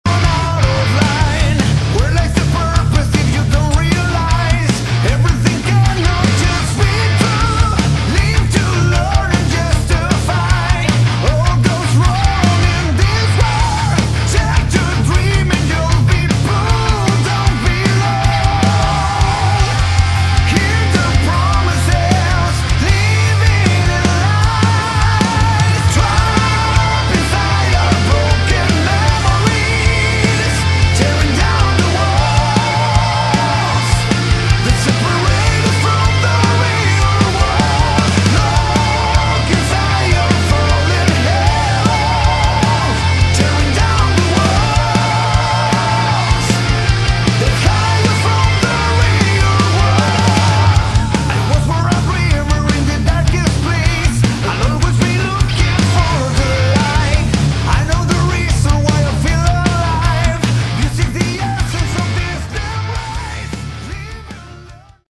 Category: Melodic Metal
vocals
guitar
keyboards, backing vocals
bass
drums